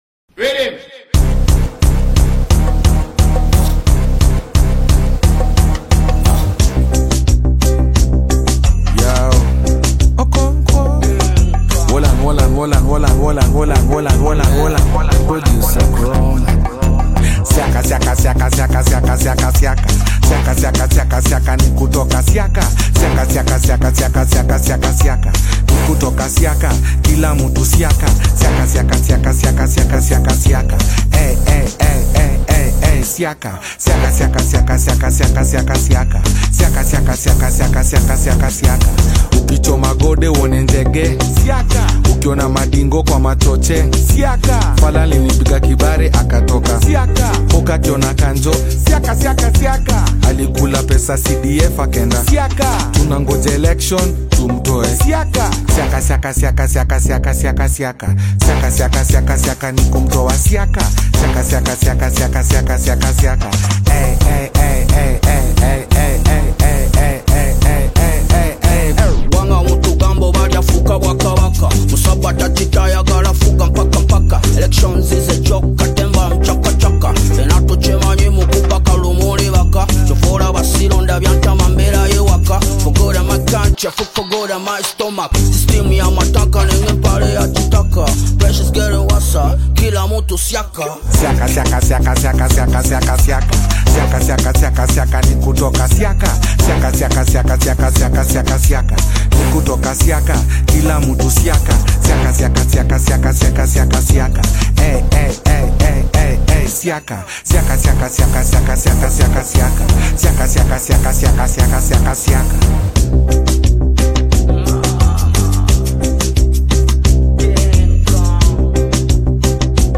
Kenyan rap sensation
powerful flow and signature sound